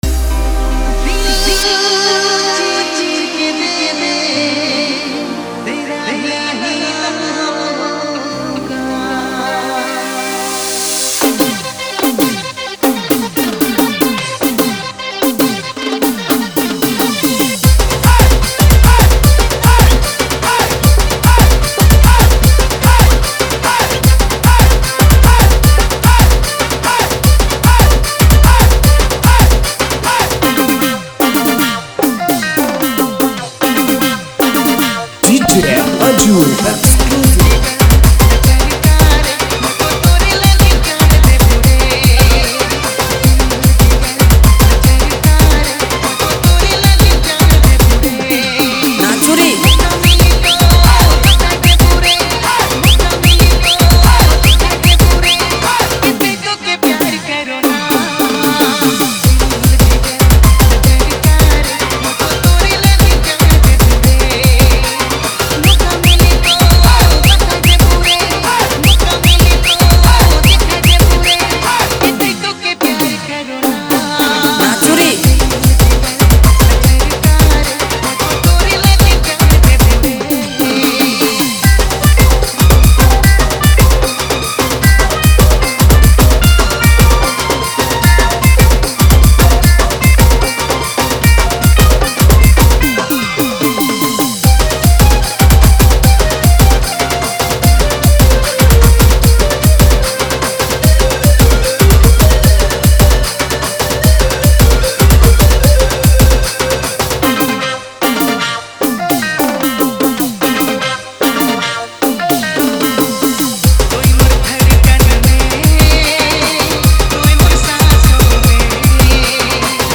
Nagpuri Version Mix